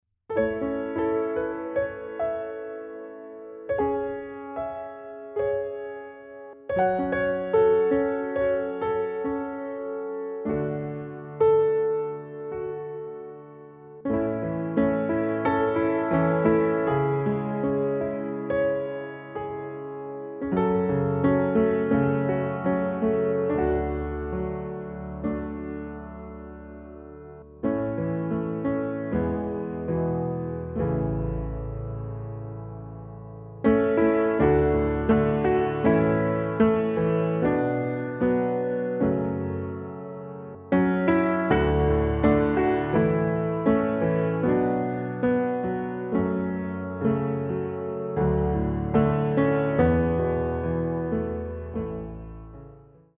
Dzwonek z muzyką klasyczną wykonaną na pianinie.